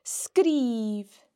The slender sgr can be heard in sgrìobh (wrote):